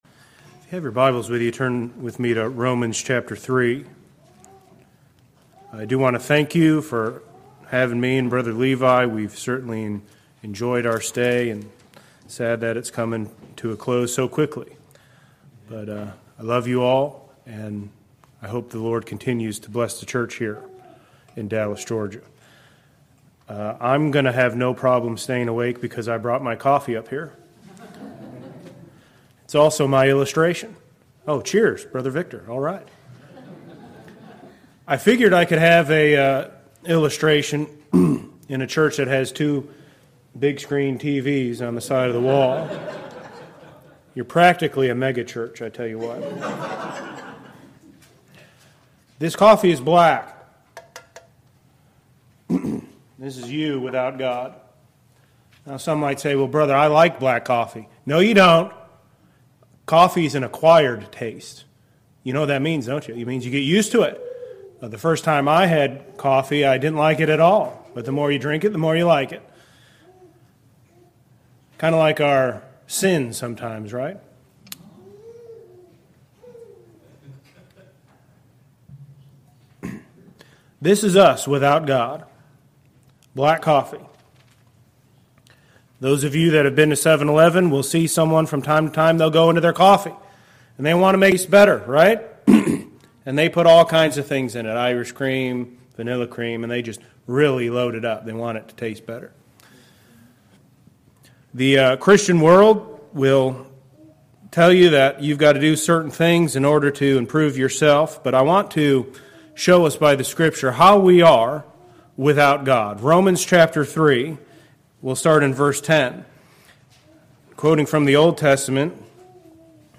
Saturday Afternoon of 2025 Annual Meeting